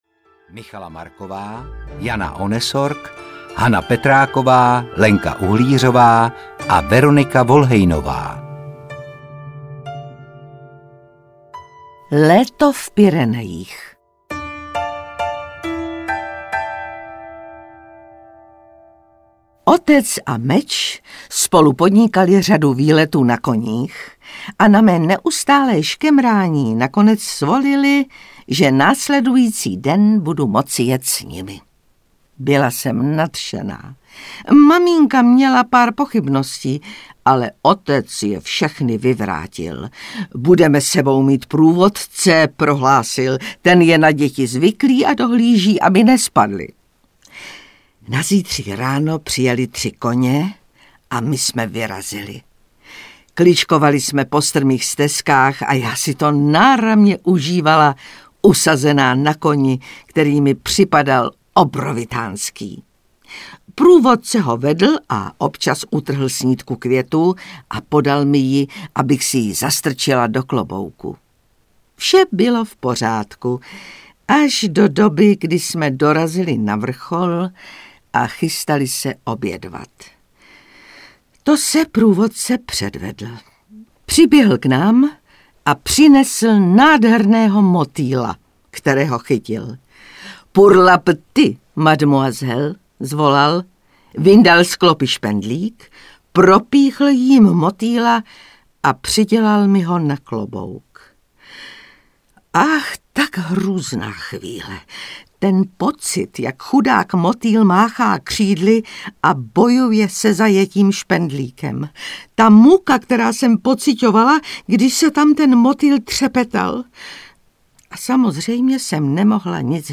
Letní záhady audiokniha
Ukázka z knihy